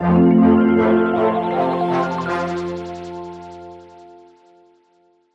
Звуки победы в игре
Звук пройденного уровня